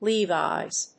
音節Le・vi''s 発音記号・読み方
/líːvɑɪz(米国英語), ˈli:vaɪz(英国英語)/